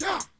Never Forget Vox.wav